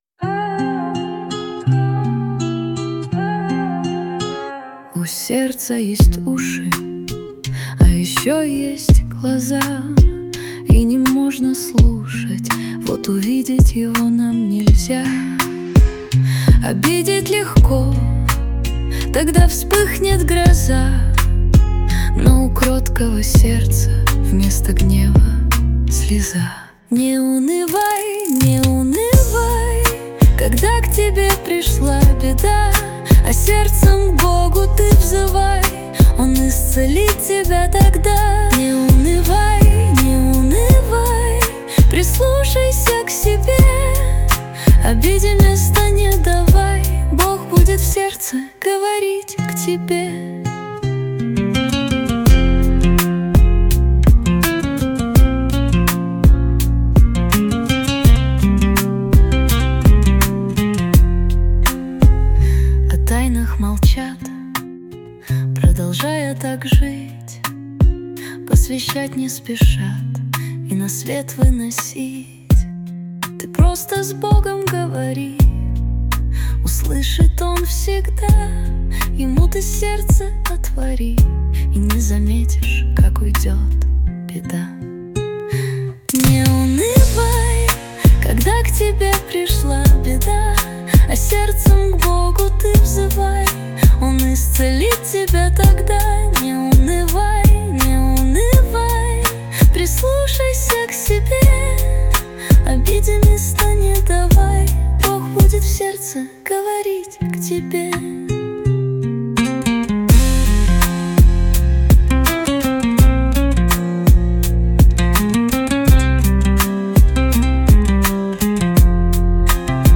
песня ai
189 просмотров 986 прослушиваний 110 скачиваний BPM: 83